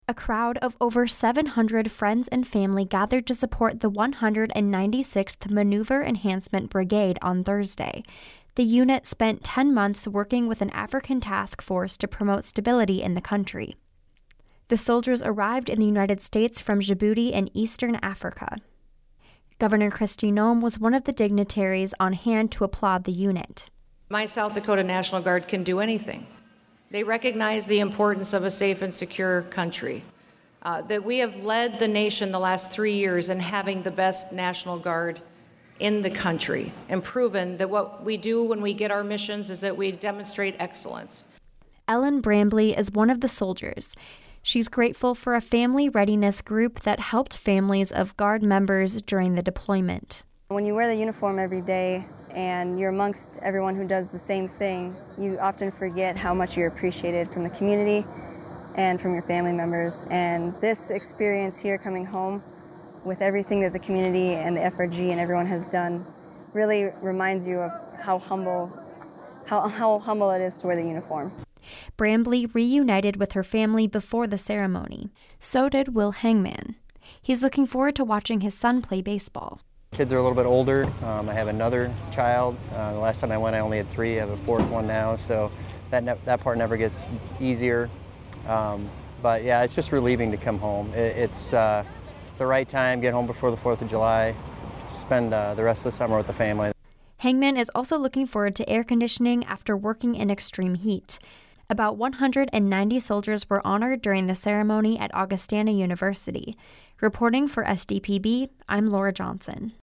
A crowd of over 700 friends and family gathered to support the 196th Maneuver Enhancement Brigade.
About 190 soldiers were honored during the ceremony at Augustana University.